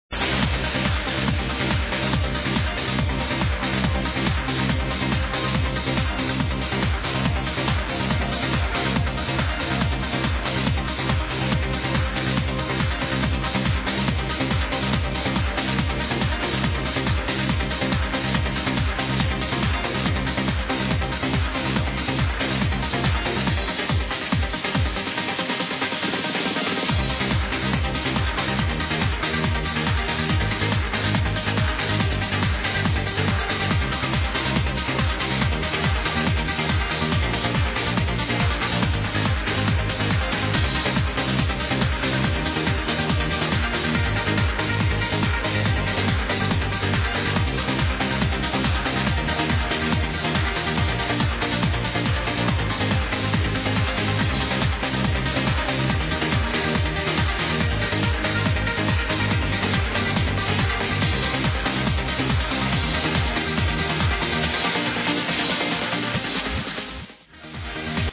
gets cut off for a commecial
Live from Casino, Berlin